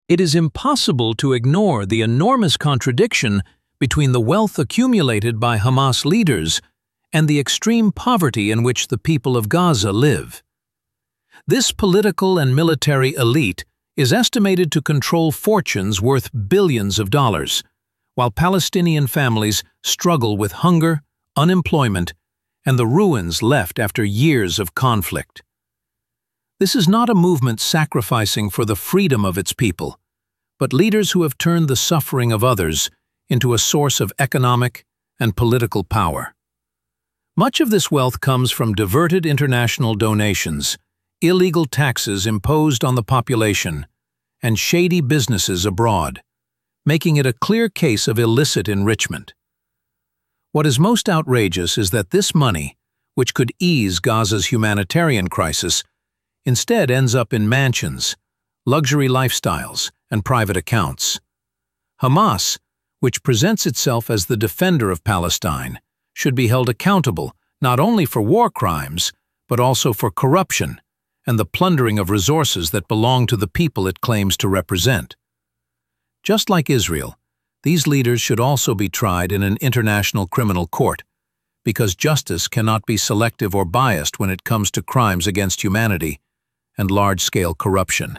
🎧 Listen • ⬇ Download • ☕ Opinion in Voice